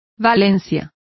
Complete with pronunciation of the translation of valency.